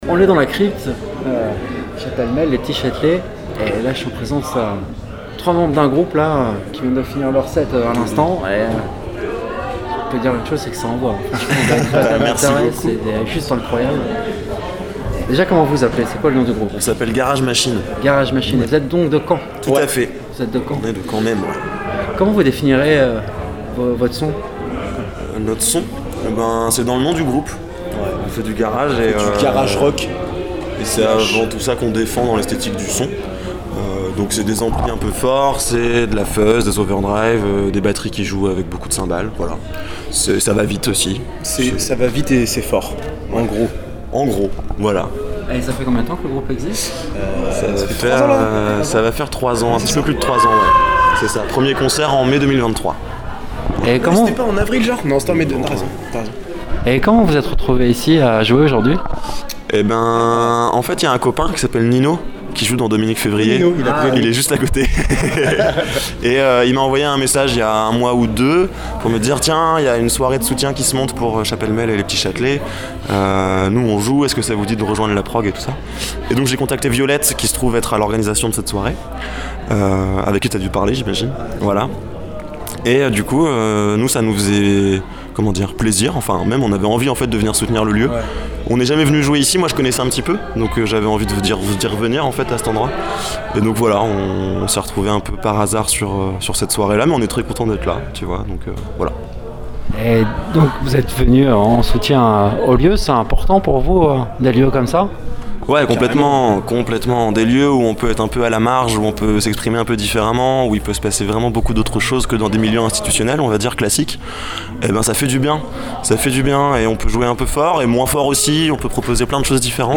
Interview réalisée lors du Grand Chahut aux Les Petits Châtelets, un événement de soutien à ce lieu culturel et associatif aujourd’hui menacé. Le groupe revient sur son univers, ses influences et l’importance de ces espaces pour faire vivre la musique locale.
Un échange authentique, capté dans l’ambiance du live, au plus près des artistes et de celles et ceux qui font battre le cœur de la scène indépendante.